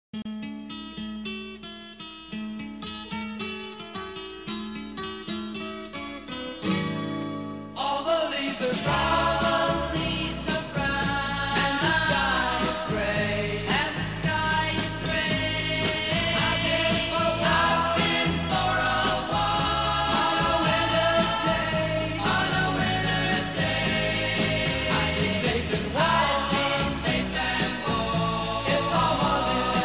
Click on the audio symbol against each picture to hear some music from the era in which the photo was taken.